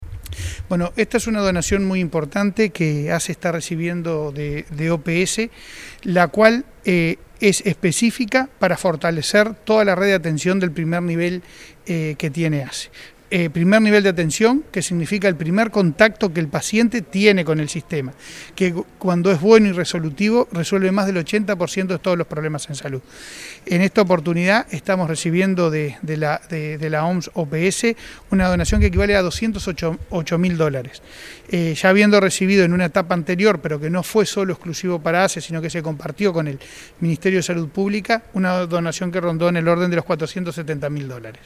Declaraciones a la prensa del presidente de ASSE, Leonardo Cipriani, sobre la donación de equipos médicos para fortalecer el primer nivel de atención